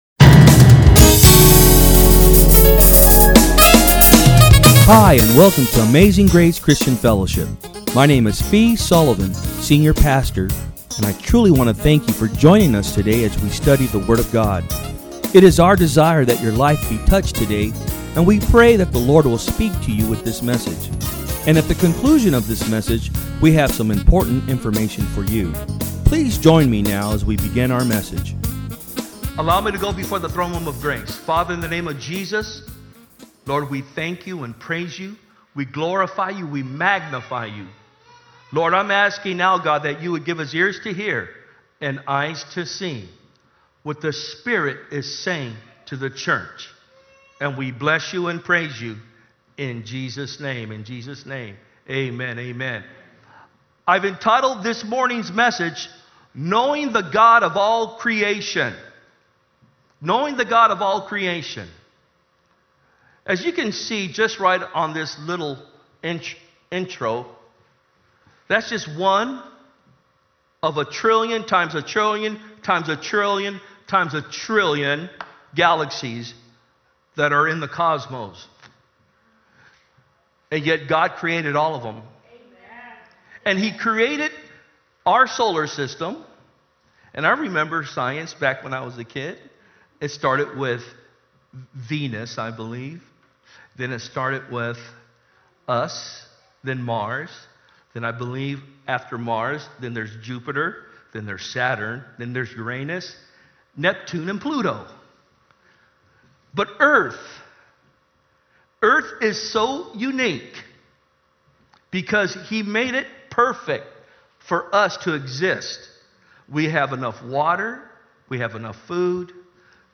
Message
From Service: "Sunday Am"